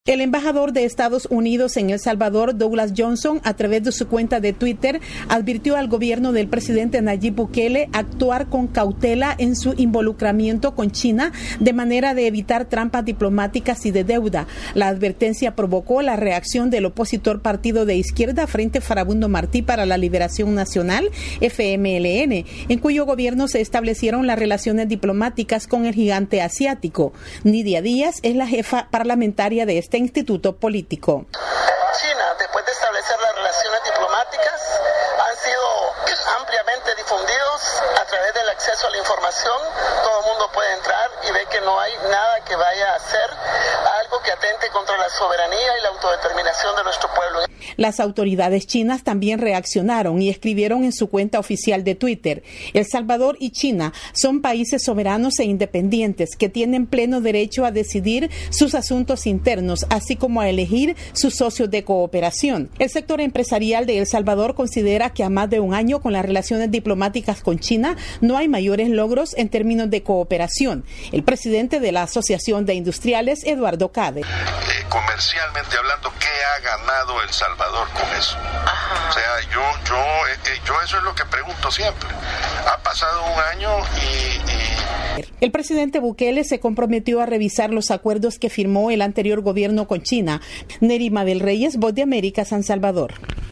VOA: Informe de El Salvador